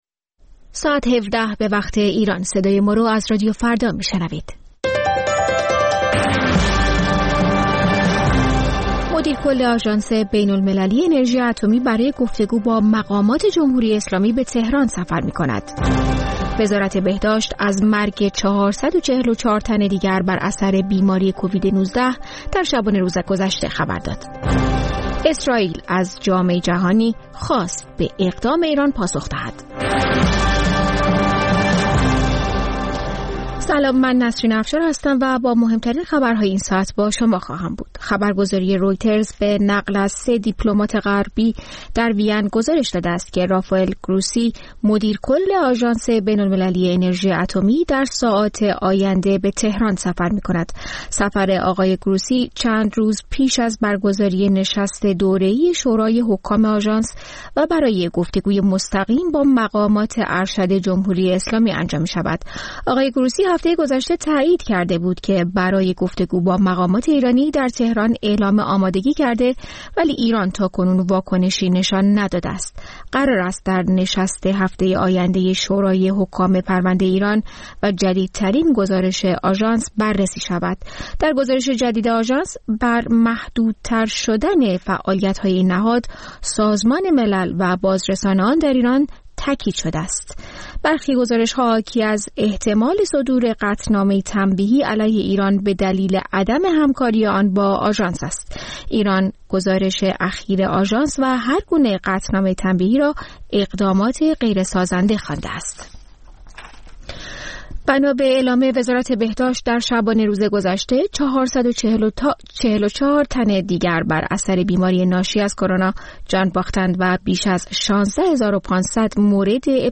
خبرها و گزارش‌ها ۱۷:۰۰